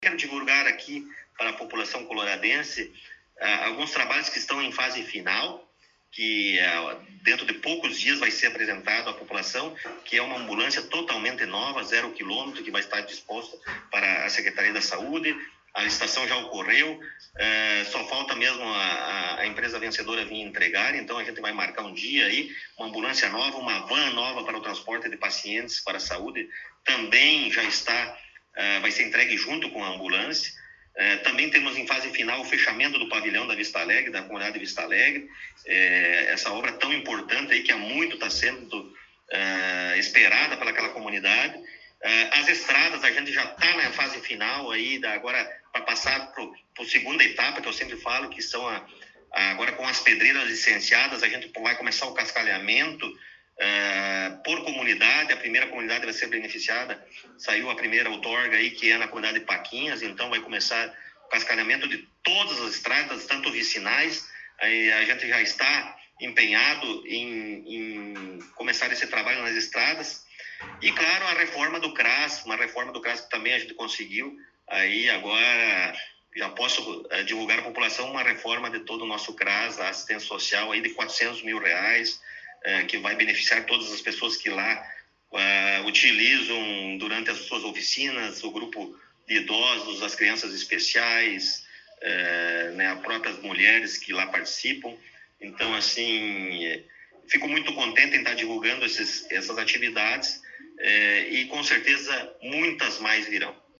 Prefeito Municipal Rodrigo Sartori concedeu entrevista